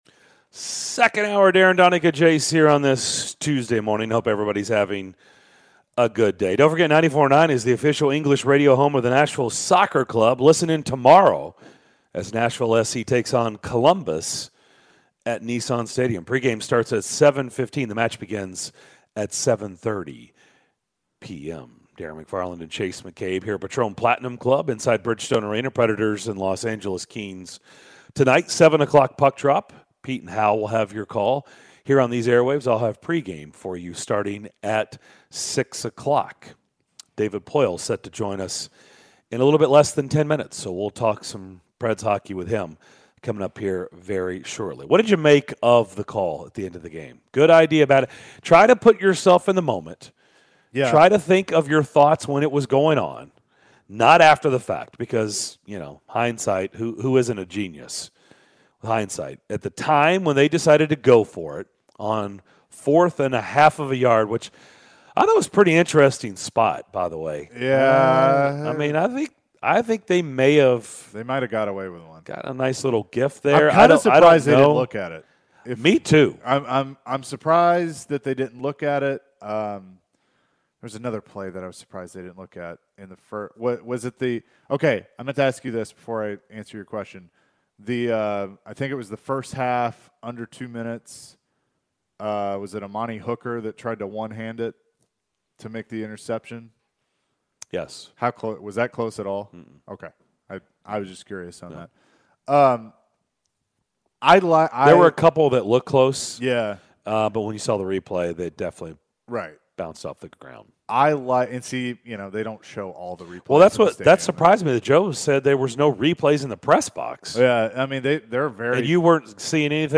Nashville Predators GM David Poile joined the show to discuss the progress of the team's young players, his team playing against Viktor Arvidsson and more ahead of tonight's game against the Kings!